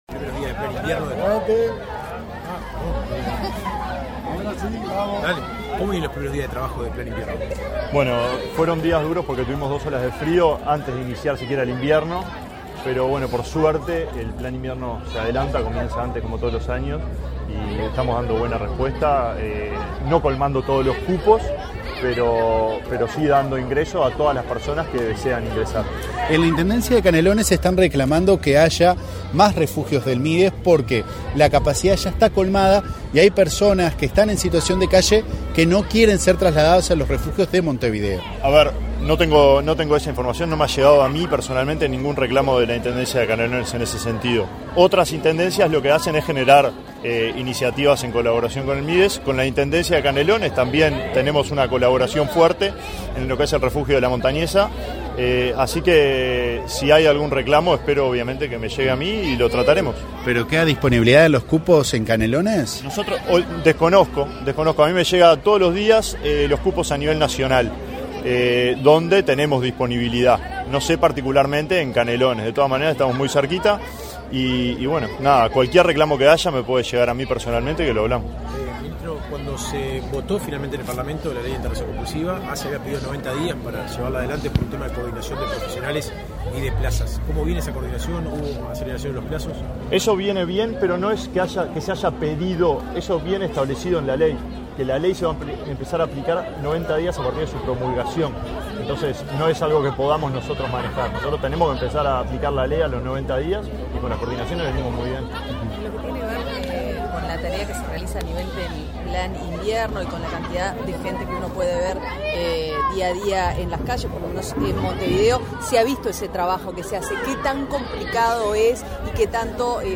Declaraciones a la prensa del ministro de Desarrollo Social, Alejandro Sciarra
Declaraciones a la prensa del ministro de Desarrollo Social, Alejandro Sciarra 30/05/2024 Compartir Facebook X Copiar enlace WhatsApp LinkedIn El titular del Ministerio de Desarrollo Social (Mides), Alejandro Sciarra, participó, este 30 de mayo, en la inauguración de una biblioteca en el Centro de Referencia de Políticas Sociales Aparicio Saravia. Antes del evento, el ministro realizó declaraciones a la prensa.